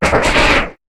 Cri d' Arcko dans Pokémon HOME .